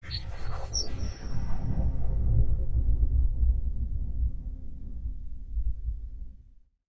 Sci-Fi Ambiences
AFX_IONSTORM_1_DFMG.WAV